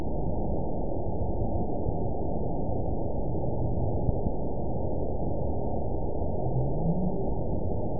event 920580 date 03/30/24 time 23:30:40 GMT (1 year, 1 month ago) score 9.43 location TSS-AB03 detected by nrw target species NRW annotations +NRW Spectrogram: Frequency (kHz) vs. Time (s) audio not available .wav